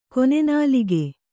♪ kone nalige